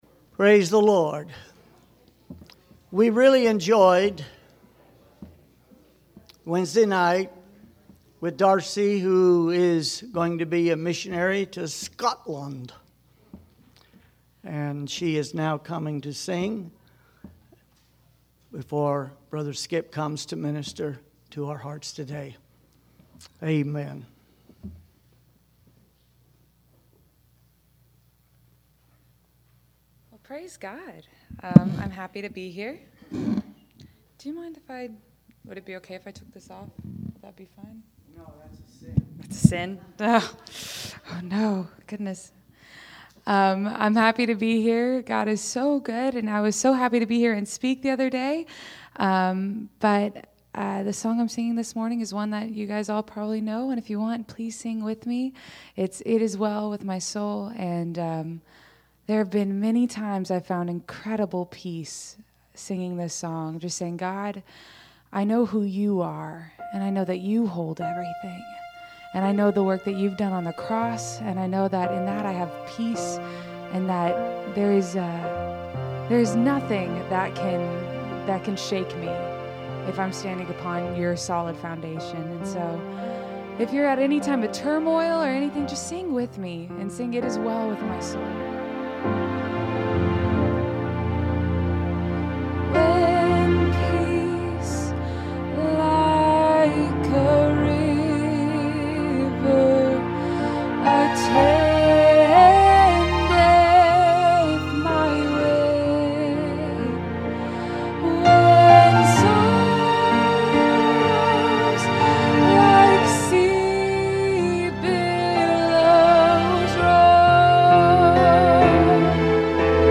Special song